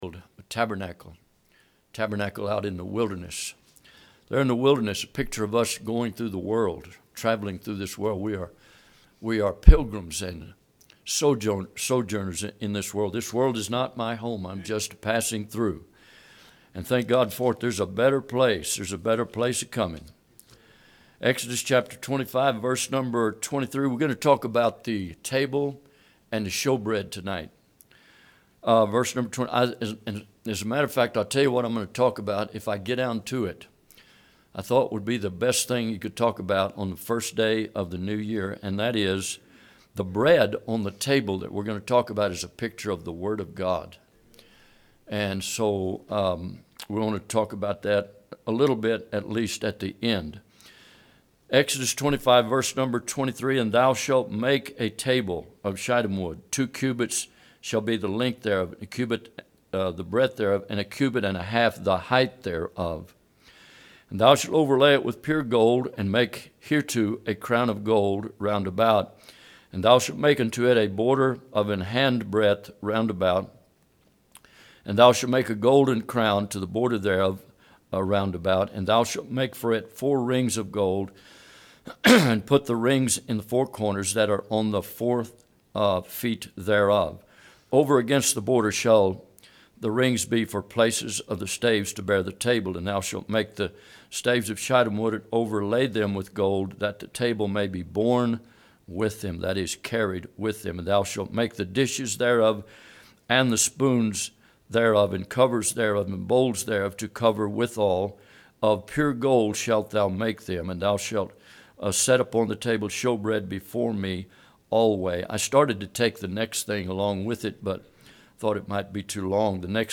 Exodus 25:23-30 Service Type: Midweek Bible Text